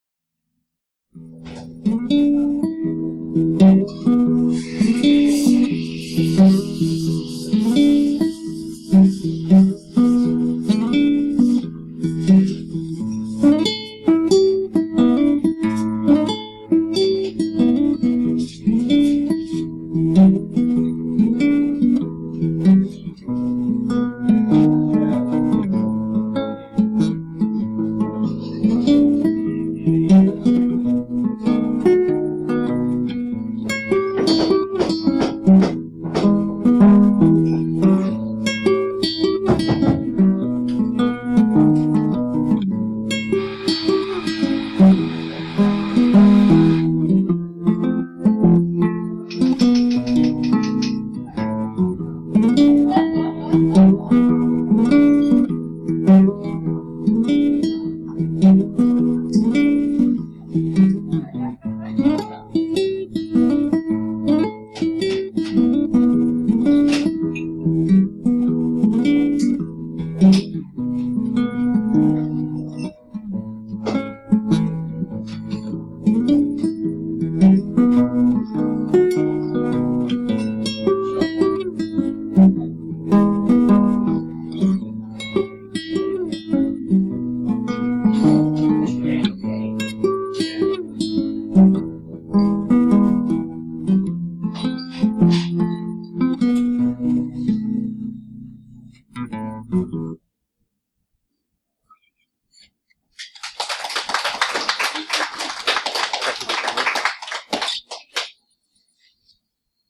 Kytarové sólo